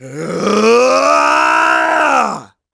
Riheet-Vox_Casting4.wav